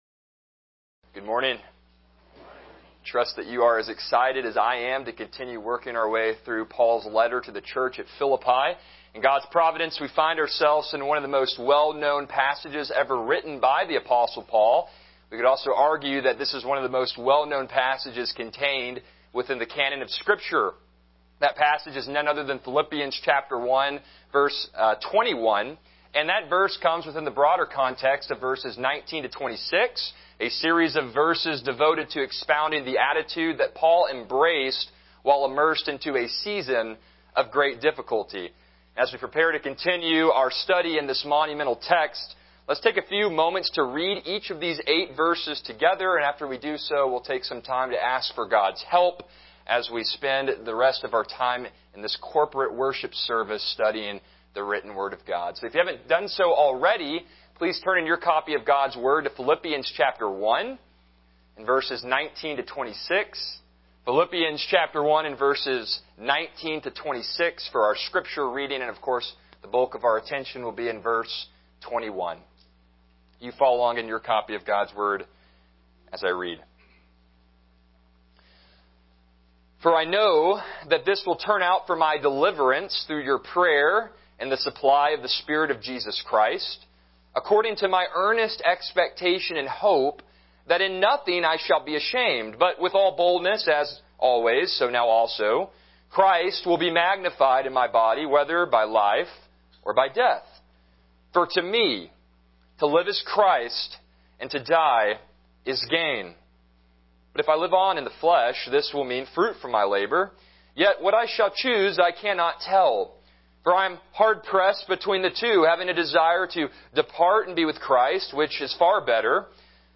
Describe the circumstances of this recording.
Passage: Philippians 1:21 Service Type: Morning Worship